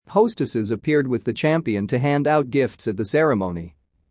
このままの速度でお聞きください。
【ノーマル・スピード】